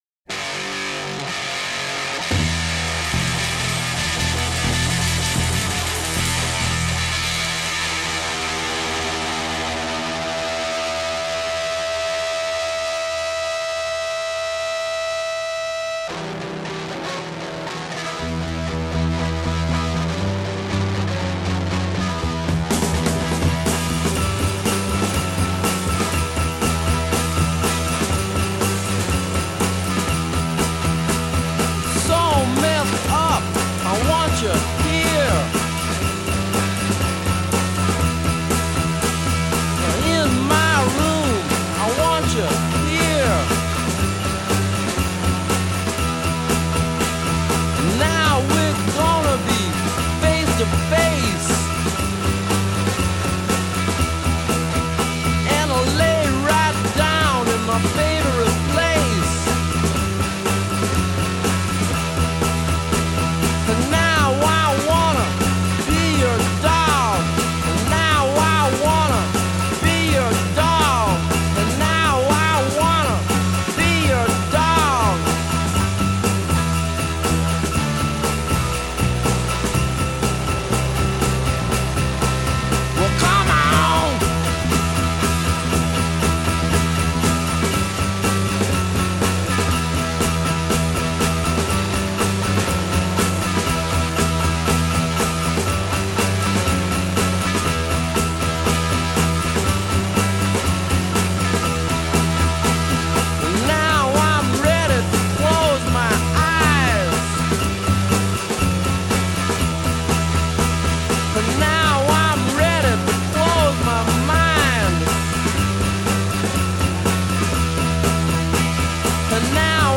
Жанр: Поп-музыка / Рок / Для тренировок